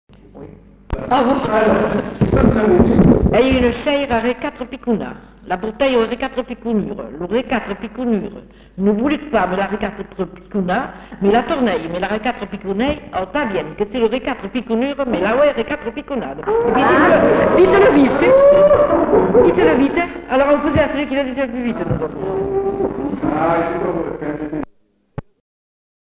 Lieu : Villandraut
Genre : forme brève